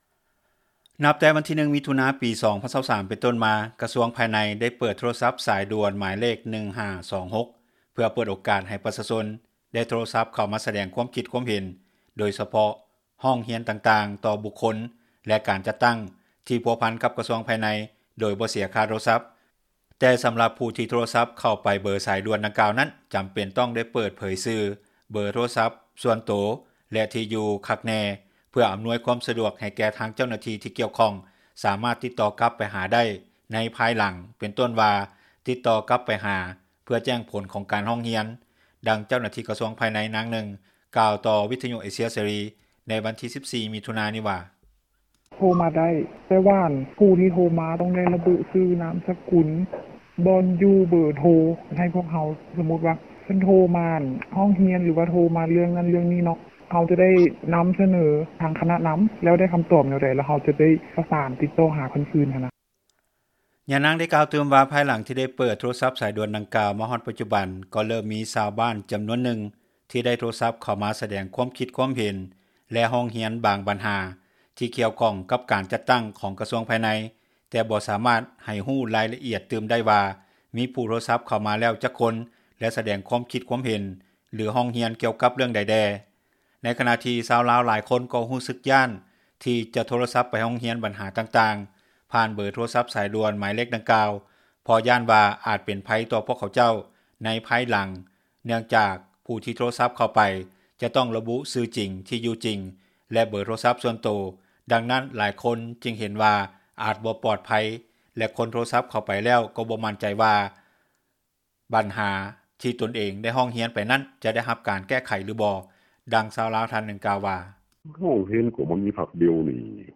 ດັ່ງເຈົ້າໜ້າທີ່ ກະຊວງພາຍໃນ ນາງນຶ່ງ ກ່າວຕໍ່ ວິທຍຸ ເອເຊັຽ ເສຣີ ໃນວັນທີ 14 ມິຖຸນານີ້ວ່າ: